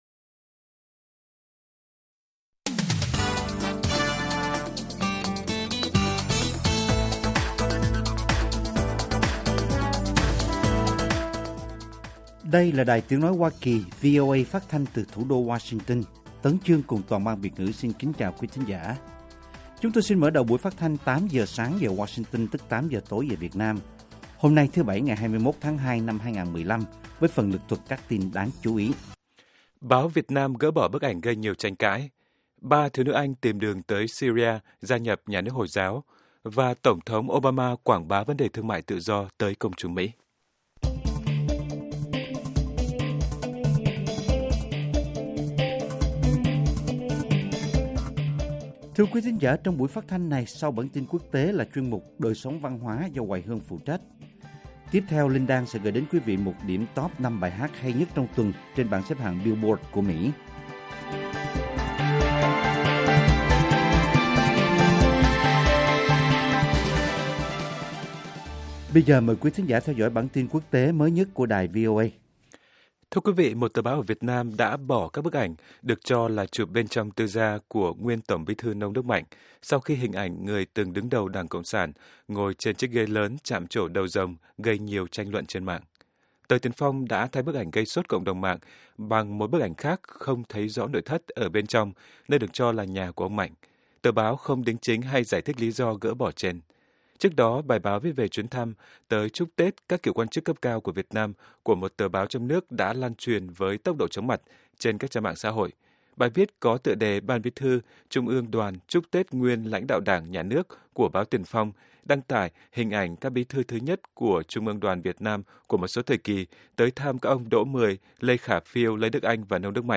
Tin tức luôn cập nhật, thời sự quốc tế, và các chuyên mục đặc biệt về Việt Nam và thế giới. Các bài phỏng vấn, tường trình của các phóng viên VOA về các vấn đề liên quan đến Việt Nam và quốc tế.